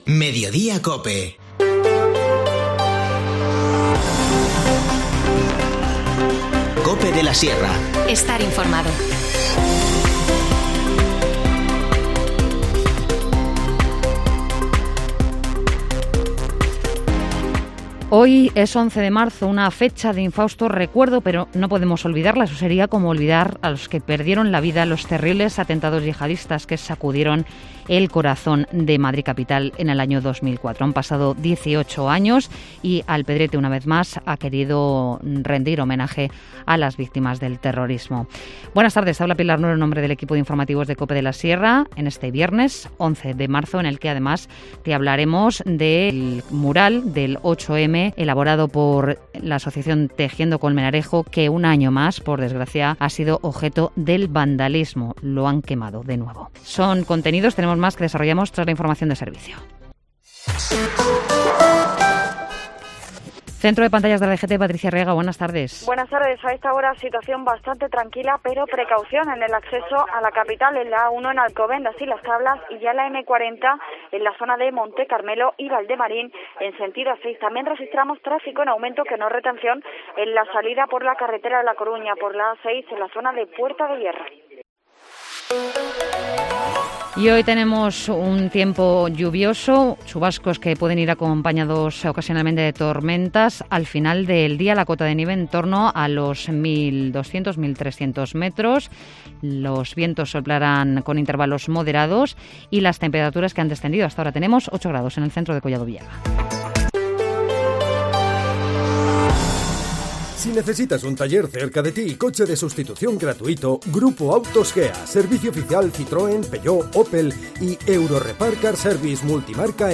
Informativo Mediodía 11 marzo